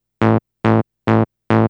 Dance / Bass / SNTHBASS014_DANCE_140_A_SC3.wav
1 channel